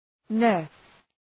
Προφορά
{nɜ:rs}